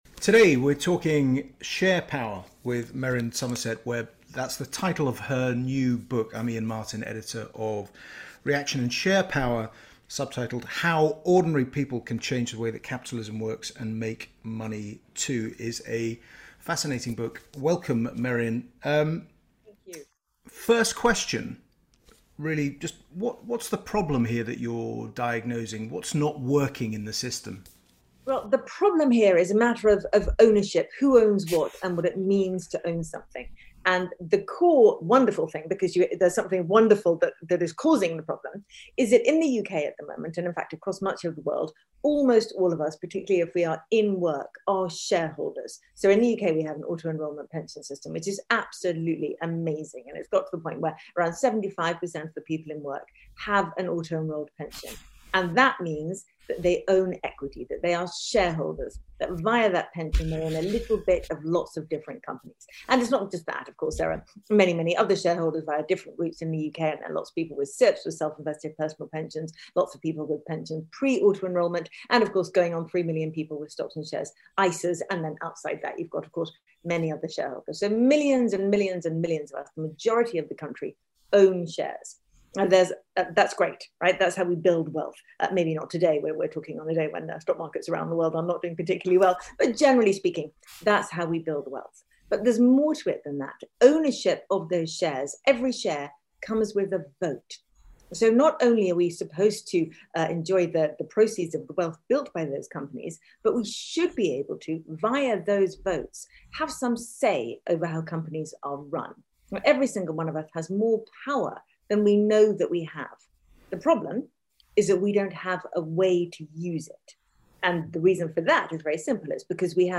Merryn Somerset Webb talks to Reaction editor Iain Martin about her new book Share Power: How ordinary people can change the way that capitalism works - and make money too.